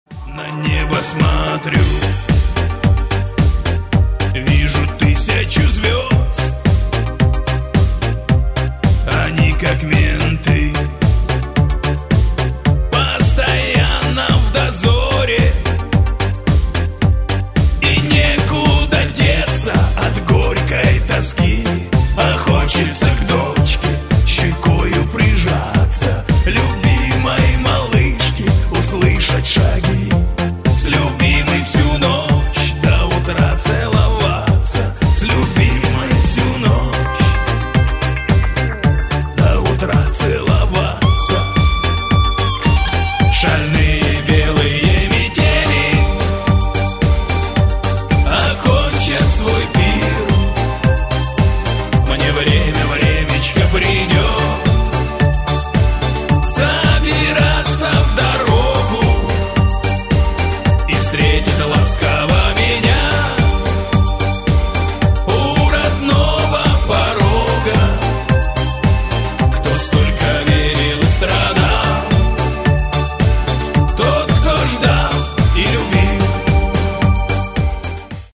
• Качество: 128, Stereo
мужской вокал
громкие
душевные
грустные
спокойные
русский шансон
хриплый голос